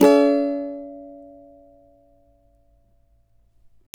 CAVA D#MN  D.wav